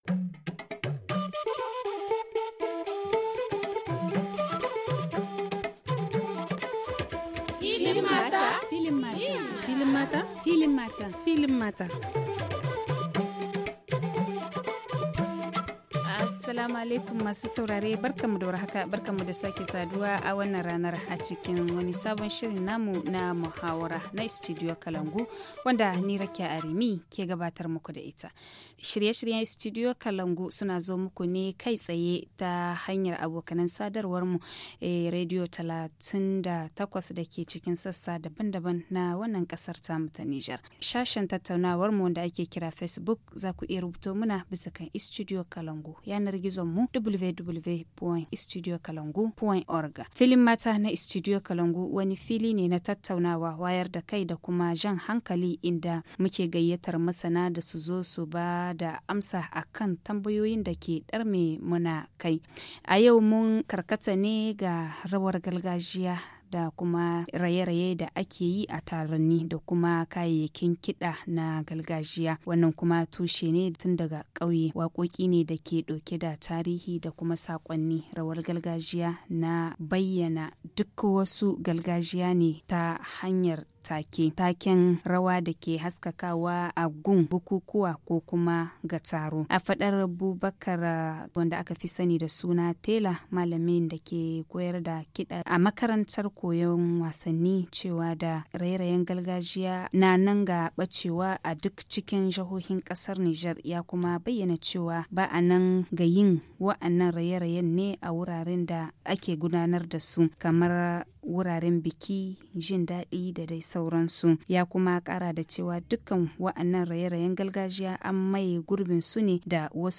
Le forum en haoussa du 27/08/2019 - Quand la danse traditionnelle est recoupée et transformée, quelles conséquences sur l’existant ? - Studio Kalangou - Au rythme du Niger